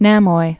(nămoi)